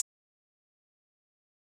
[HiHat] Dro Main.wav